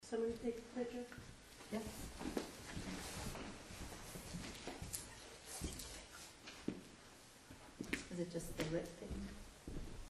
Someone is getting ready to take a photo when a soft whispered voice is heard.